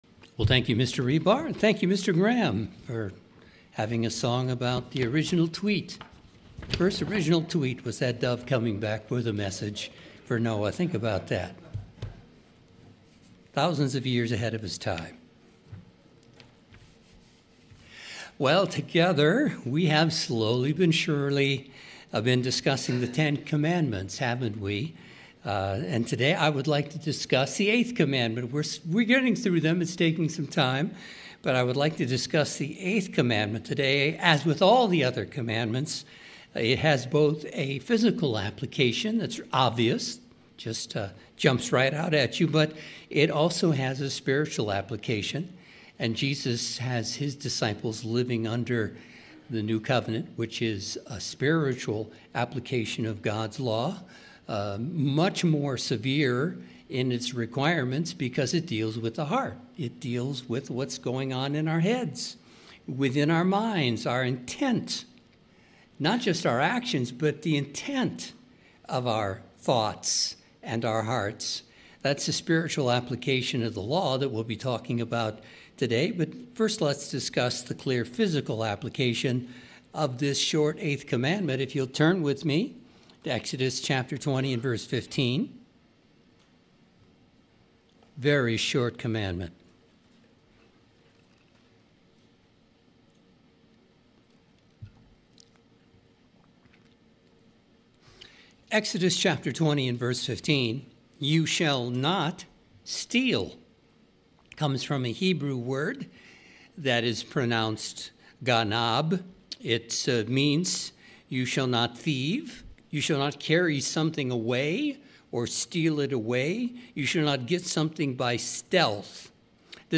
sermon_you_shall_not_steal.mp3